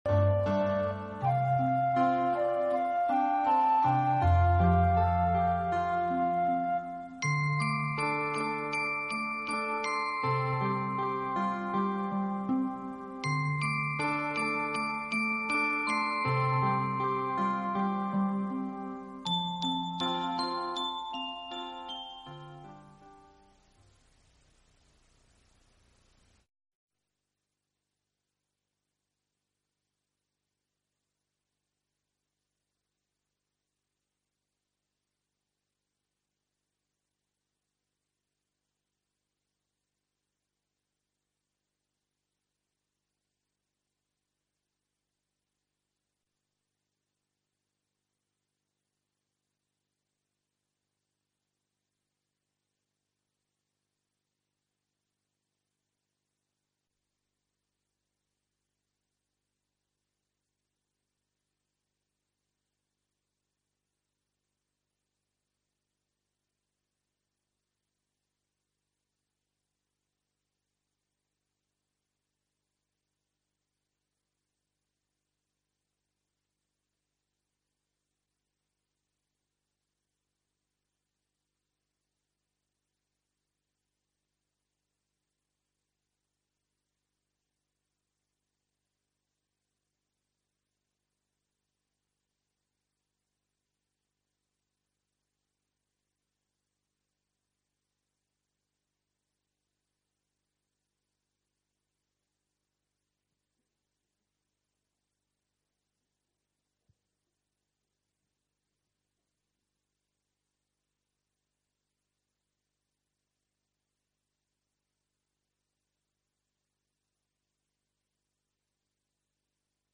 Mp3 thuyết pháp Pháp là đối tượng của tâm thức - Định đề 30; 31 do HT. Thích Nhất Hạnh giảng ngày 16 tháng 11 năm 2006